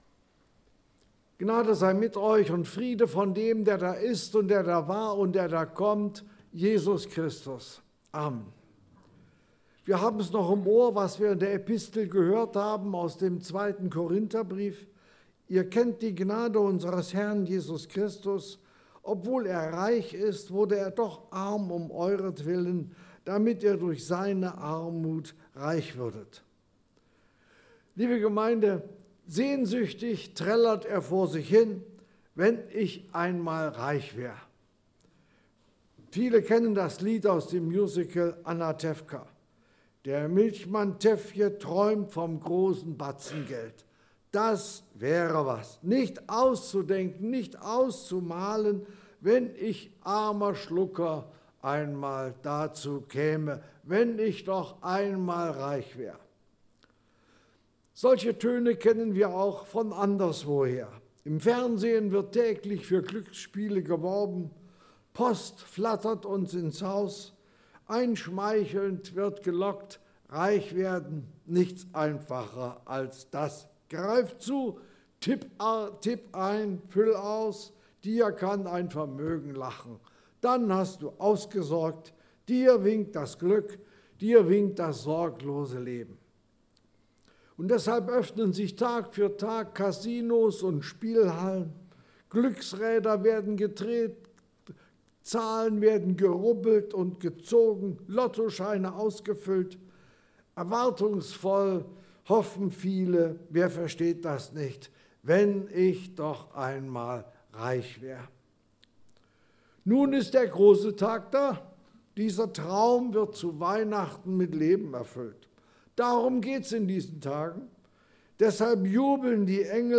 Korinther 8, 7-9 Dienstart: Gottesdienst « Das Warten hat ein Ende.